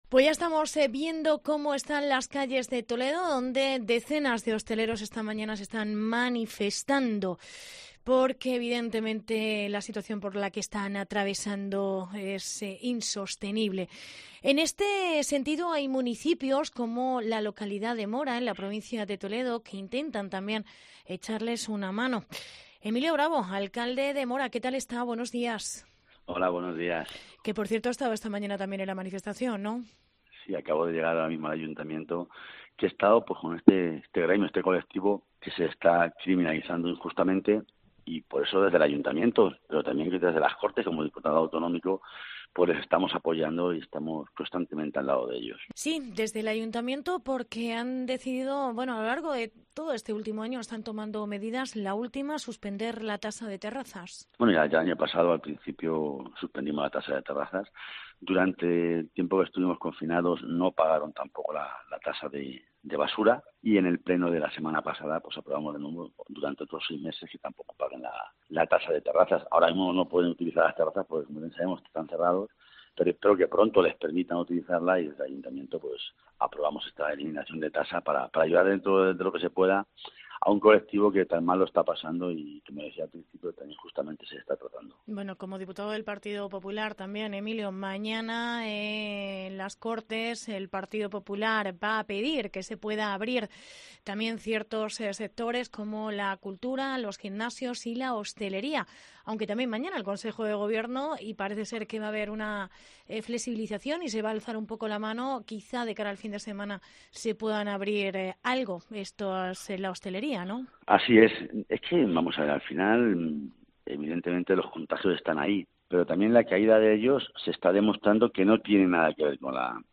Entrevista Emilio Bravo. Alcalde de Mora